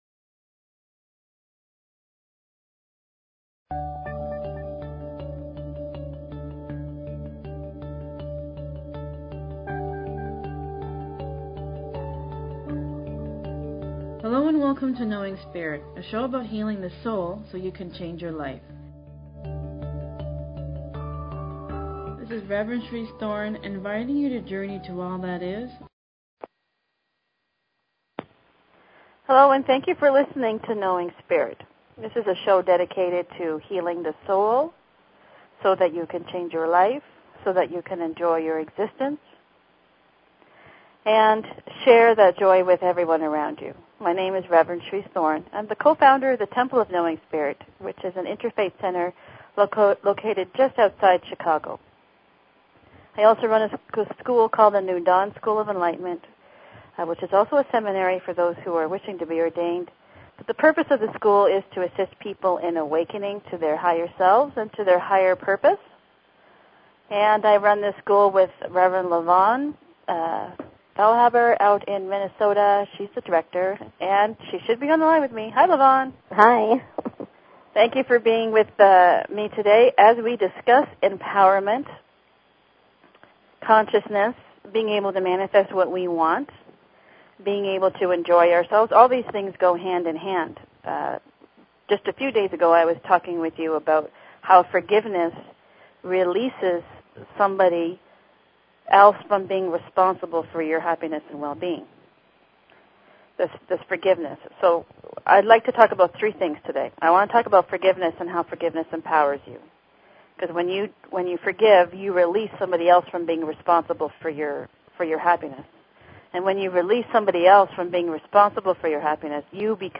Talk Show Episode, Audio Podcast, Knowing_Spirit and Courtesy of BBS Radio on , show guests , about , categorized as